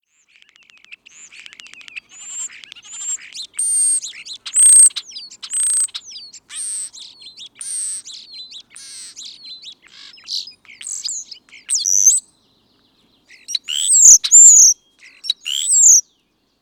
European Starling
European Starlings are great vocal mimics—individual birds can learn the calls of up to 20 different species. How they sound: Their call will vary, as they have around ten types to communicate about where they are, whether there’s danger around, and how aggressive or agitated they feel.
European_Starling_1_Song.mp3